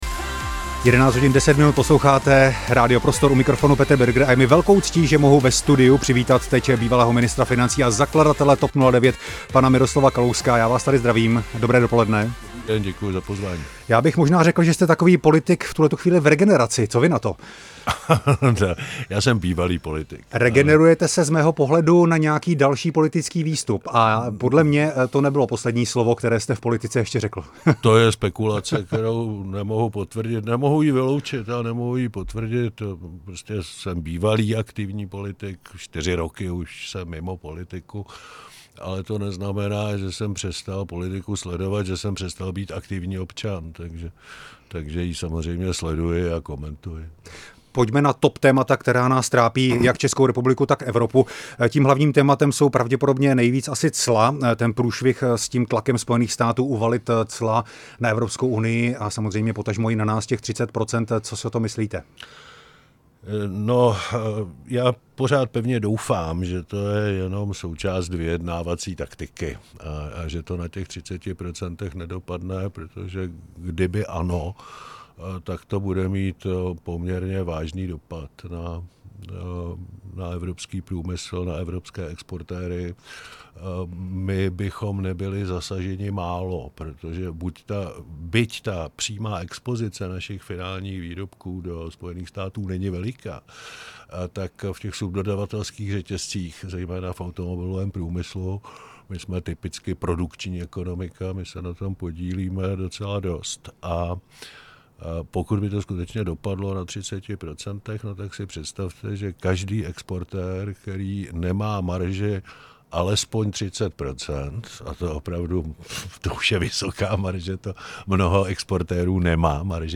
První část rozhovoru s exministrem financí Miroslavem Kalouskem